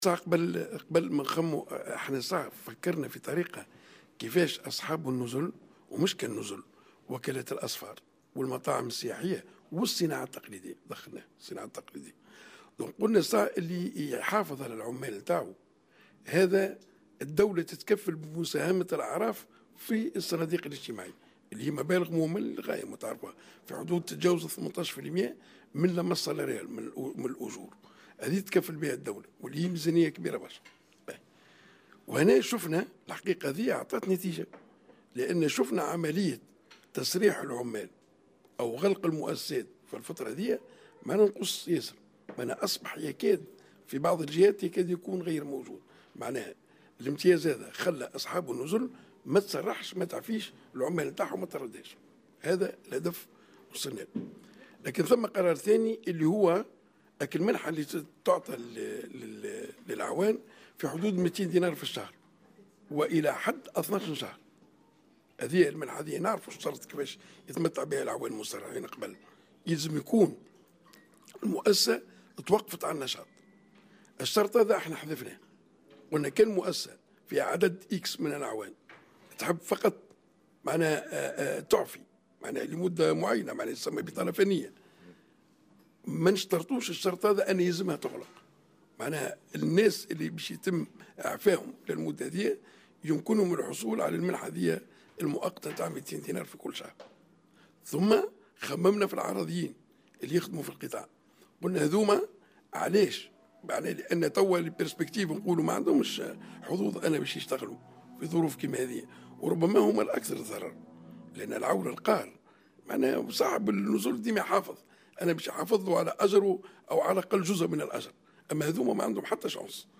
قال وزير الشؤون الاجتماعية عمار الينباعي اليوم في تصريح ل"جوهرة أف أم" إن هناك مقترحات بخصوص إسناد منحة للعمال القارين المسرّحين من القطاع السياحي تقدّر قيمتها ب200 دينار للعامل الواحد ولمدة 12 شهرا و منحة ظرفية بقيمة 150 دينار بالنسبة للعمال العرضيين لمدة 6 أشهر.